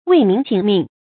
注音：ㄨㄟˋ ㄇㄧㄣˊ ㄑㄧㄥˇ ㄇㄧㄥˋ
為民請命的讀法